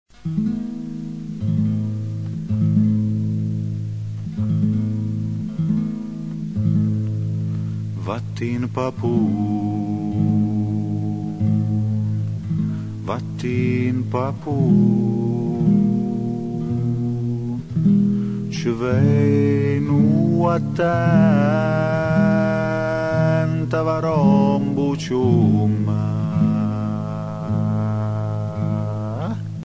Ninna nanna:
NINNA NANNE (pugliese)
Ninna_nanne_vattin_papunn-part.mp3